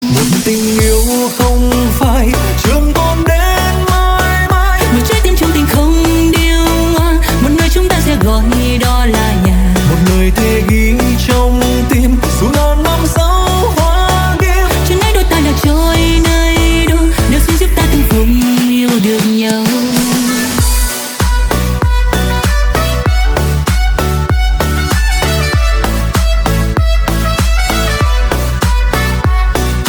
với sự hòa giọng đầy ăn ý
trên nền nhạc remix sôi động